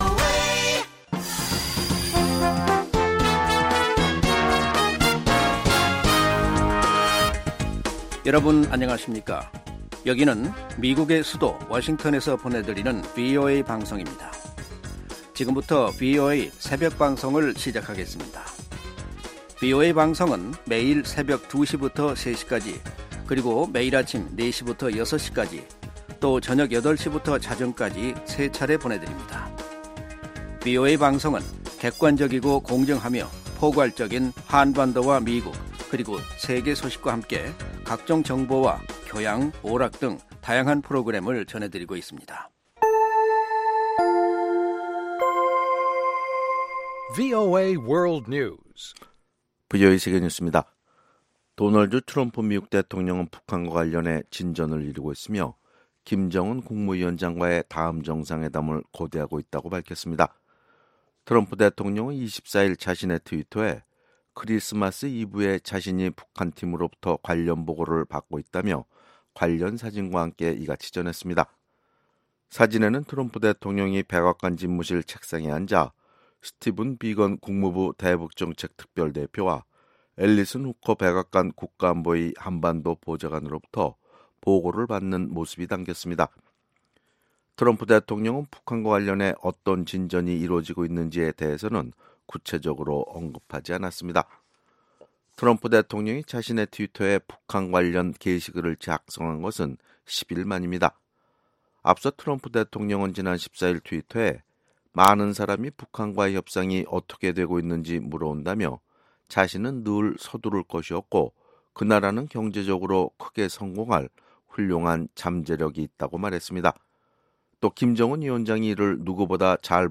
VOA 한국어 '출발 뉴스 쇼', 2018년 12월 26일 방송입니다. 미 연방법원은 북한이 오토 웜비어의 가족들에게 약 5억 달러를 배상해야 한다는 판결을 내렸습니다. 북한이 인권 문제에 대한 국제사회의 비판에 예민하게 반응하는 이유는 정권 유지 때문이라고 미국의 인권 전문가들이 말했습니다. 올해 대북제재의 영향으로 북한의 대중국 수출액이 10분의 1 수준으로 급감했습니다.